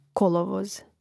Rastavljanje na slogove: ko-lo-voz